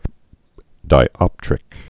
(dī-ŏptrĭk) also di·op·tri·cal (-trĭ-kəl)